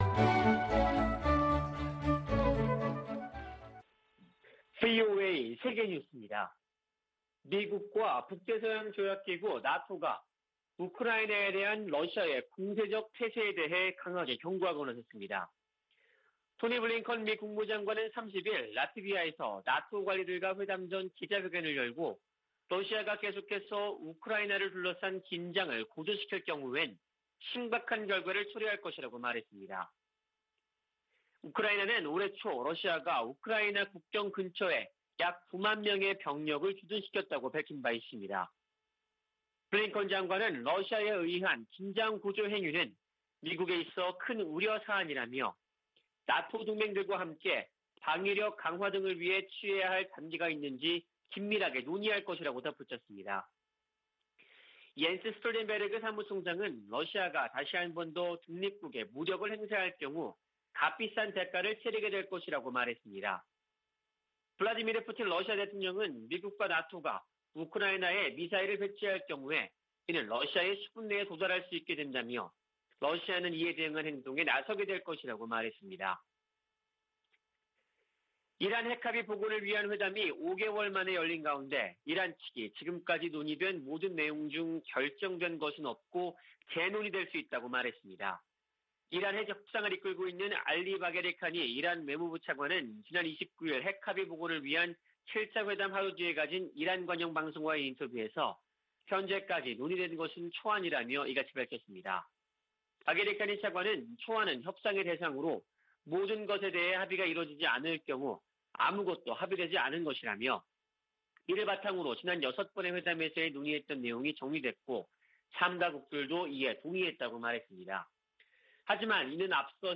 VOA 한국어 아침 뉴스 프로그램 '워싱턴 뉴스 광장' 2021년 12월 1일 방송입니다. 해외주둔 미군 재배치 검토를 끝낸 미국방부는 주한미군 규모를 현행수준으로 유지하기로 했습니다. 북한 선박들의 공해상 움직임이 늘고 있는 가운데 미 국무부는 국제사회의 대북제재 이행의 중요성을 거듭 강조했습니다. 조 바이든 행정부 출범 이후 약 10개월 넘게 주한 미국대사가 공석인 가운데 적임자 발탁이 중요하다는 견해와 인선을 서둘러야 한다는 지적도 나오고 있습니다.